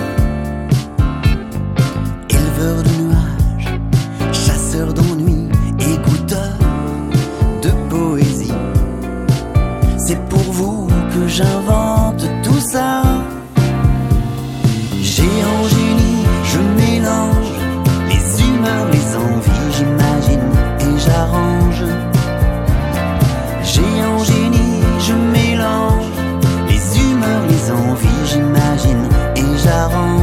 un duo de musiciens-chanteurs pour le jeune public